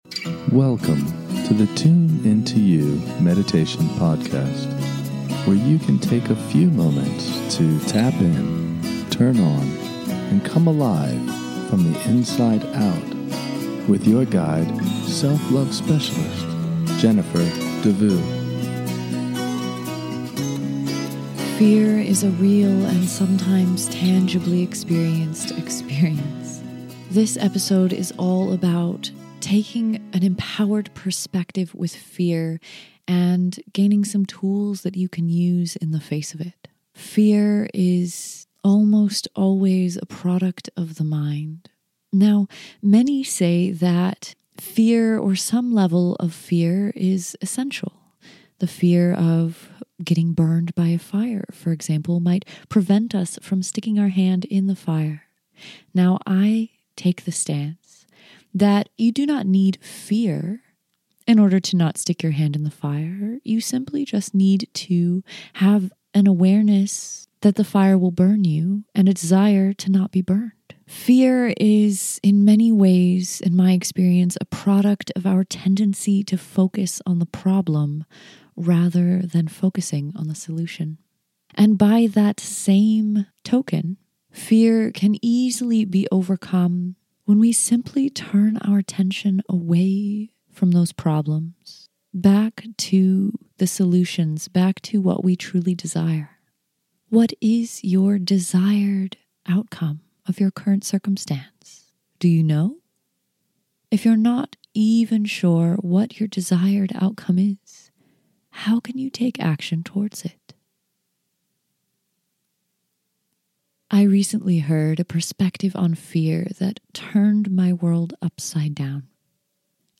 In this short guided meditation, we will talk about what fear is and how it can distract us from creating what we truly desire. In this relaxing meditation, you will be guided to transform your fear into power so that you can be a guiding light for yourself and for others.